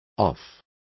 Complete with pronunciation of the translation of off.